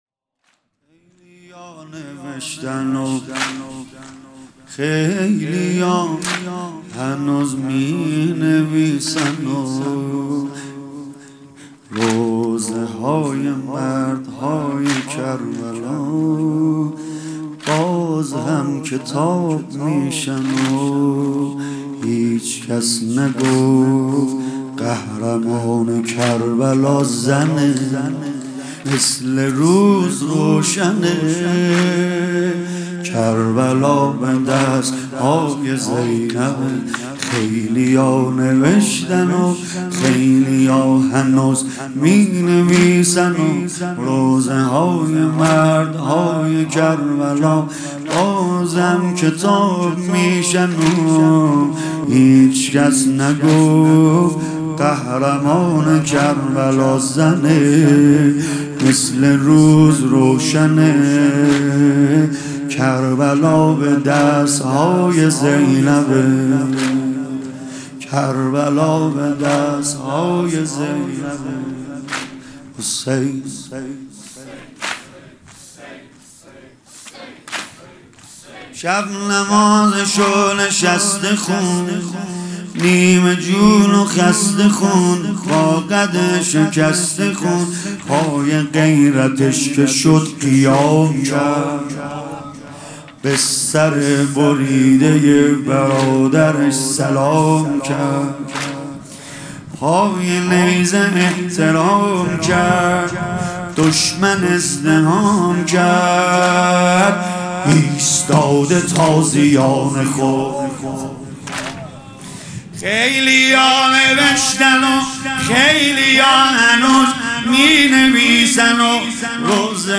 واحد | خیلی ها نوشتنو خیلی ها مینویسنو
شب دهم محرم ۹۹ - هیئت فدائیان حسین